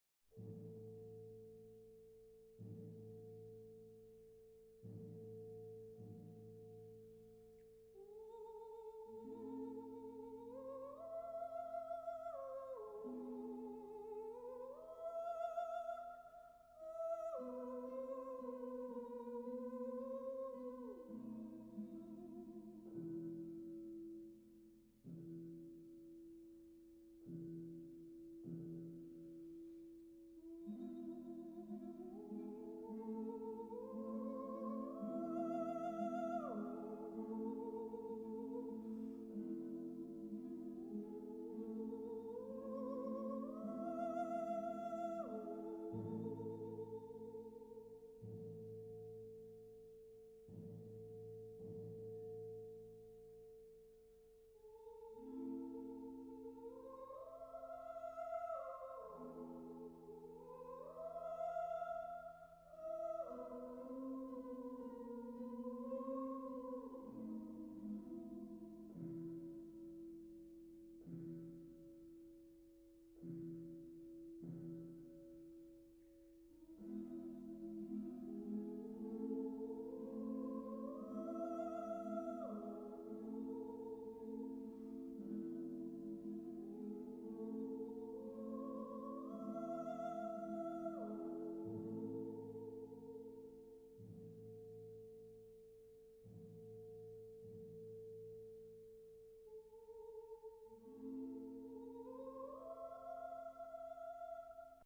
This minor-key, a bocca chiusa geisha song, accompanied by harp, remains dynamically restrained (pp or p) throughout.
This sparse song serves as an introductory gesture, an opening like an orchestral prelude that foreshadows the impending tragedy. The thin texture and the lamenting melody provide a stark contrast to the preceding act with its glorious “Inno del sole,” the luxuriant garden scene, and the exotic Japanese instrumentation of the puppet show.
Following the geisha song, Kyoto enters sotto voce, continuing the subdued, hushed tones of the preceding passage.